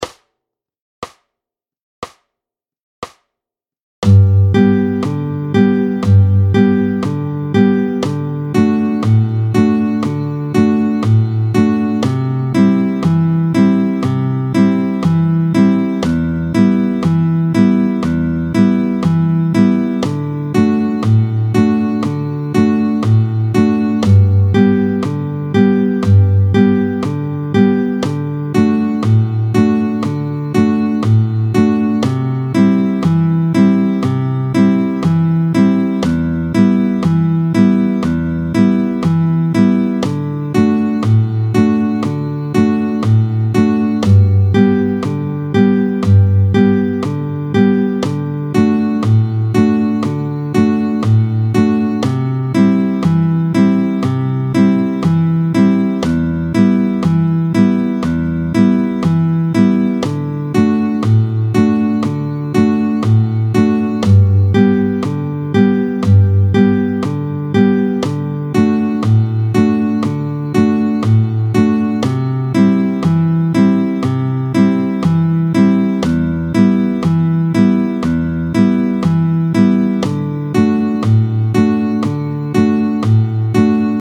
07-01 La mesure à 4/4 (à 4 temps), tempo 60